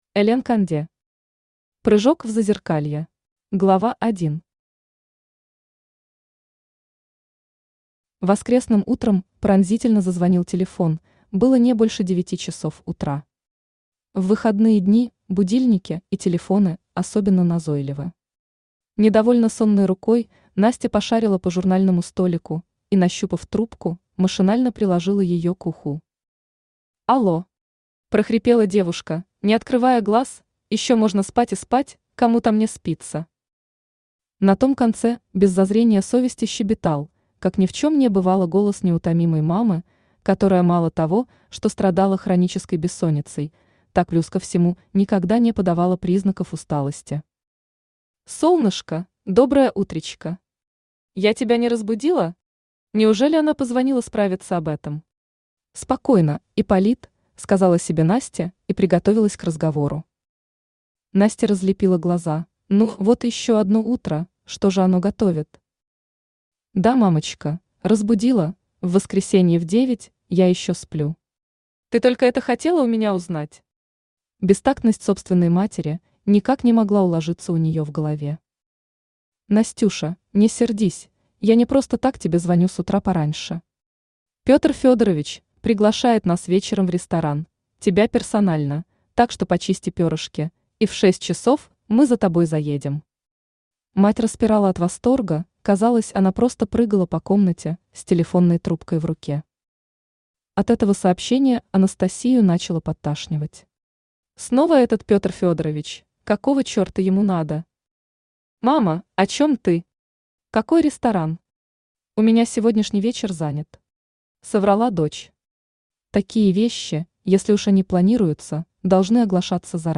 Аудиокнига Прыжок в зазеркалье | Библиотека аудиокниг
Aудиокнига Прыжок в зазеркалье Автор Элен Конде Читает аудиокнигу Авточтец ЛитРес.